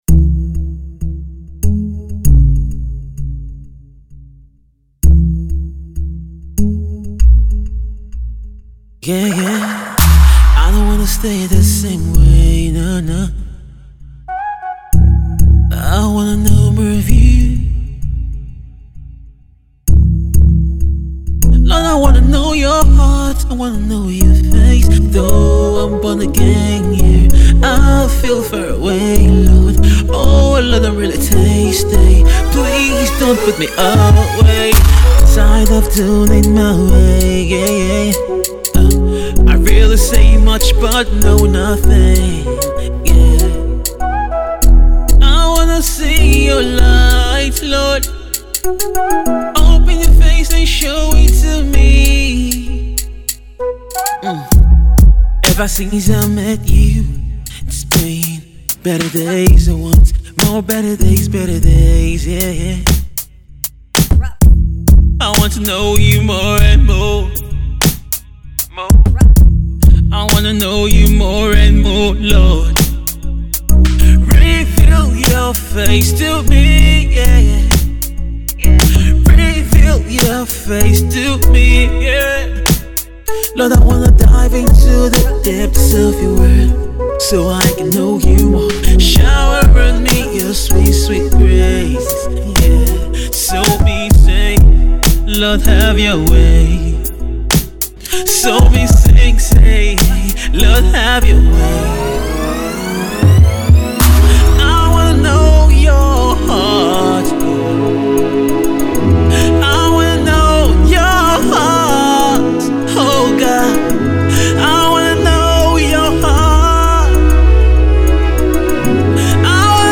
Gospel singer-songwriter